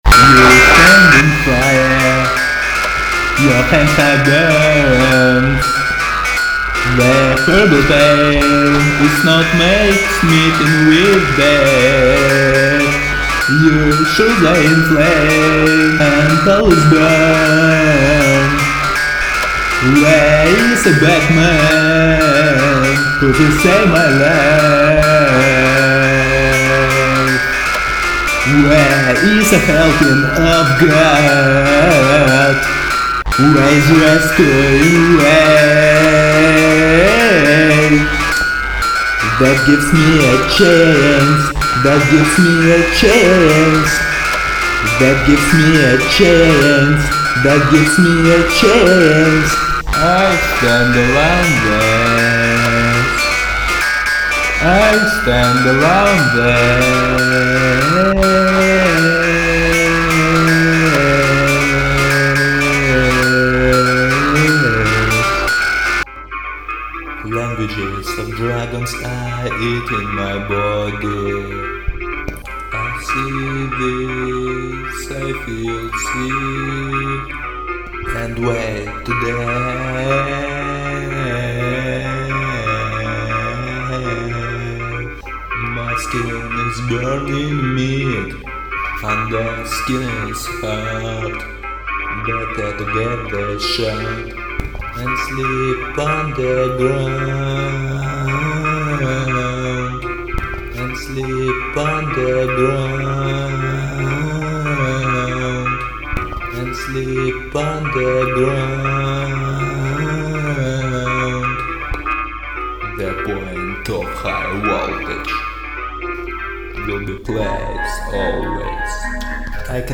Альтернативный рок